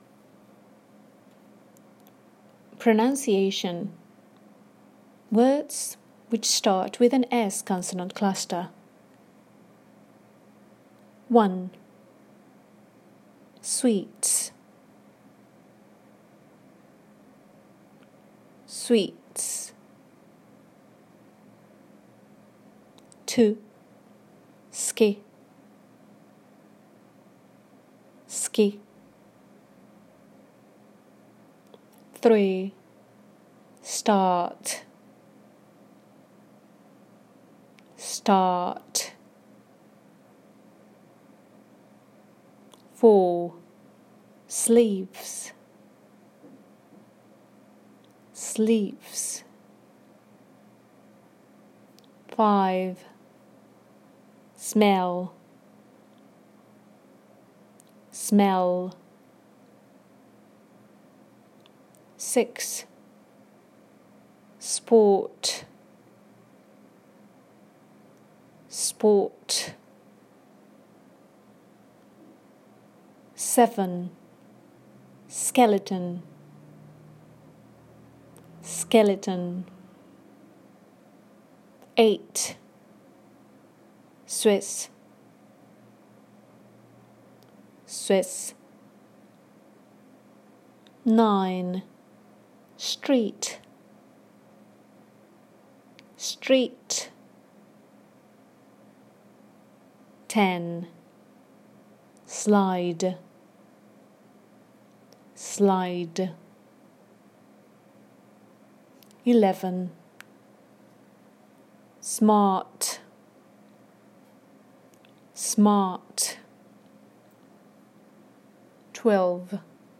You are going to listen twice to some words with an initial s cluster.